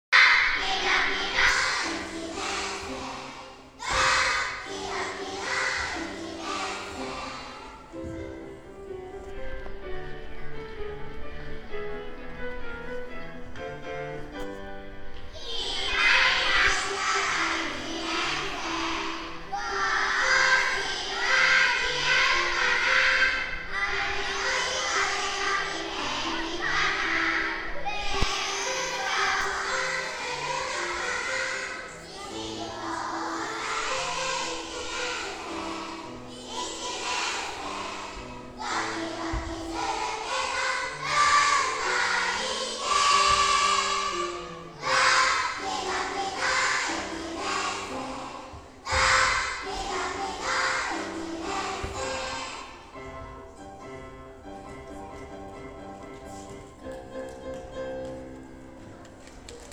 1年生を迎える会（4月18日）
合唱を披露する1年生
1年生の歌声（一部分）（音楽ファイル(MP3)：933KB）
1nengasshou.mp3